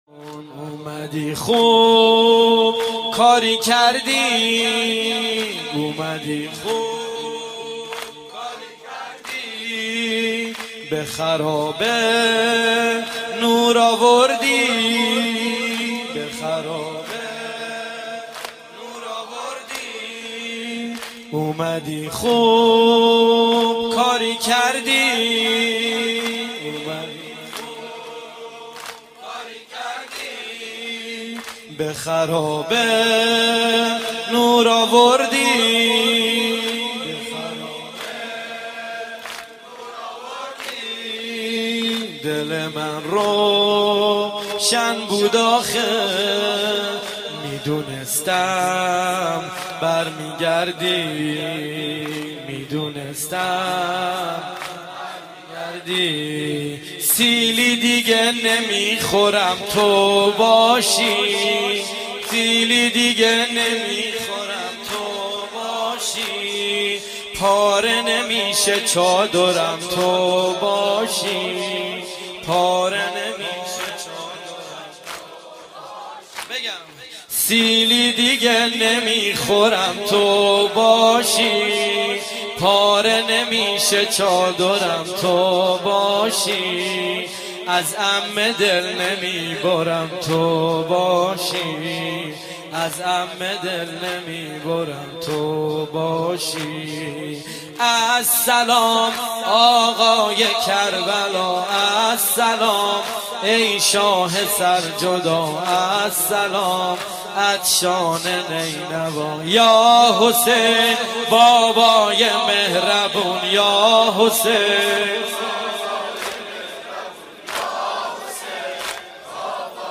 شب سوم محرم الحرام 1395
شور